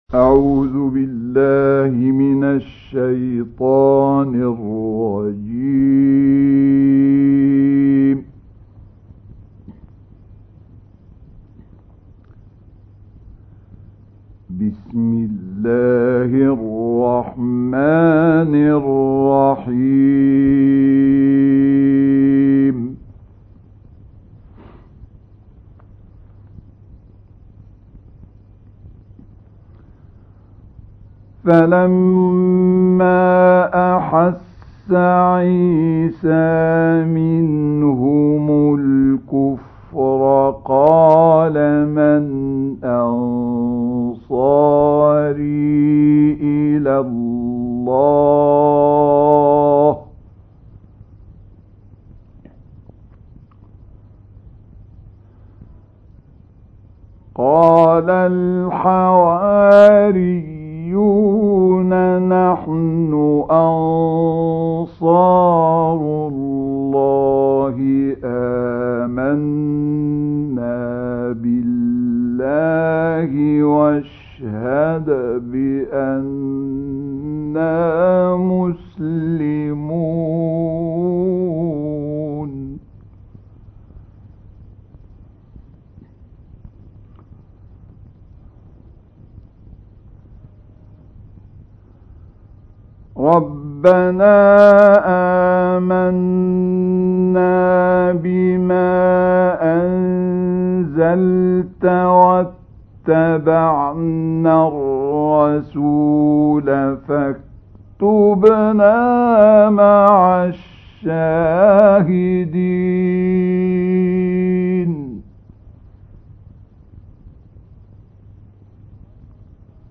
تلاوت کمتر شنیده شده از عبدالفتاح شعشاعی+صوت
در این گزارش توجه علاقه‌مندان را به استماع تلاوتی زیبا و کمتر شنیده شده از این استاد قرآن کریم شامل آیات ۵۲ تا ۷۸ سوره مبارکه «آل‌عمران» جلب می‌کنیم.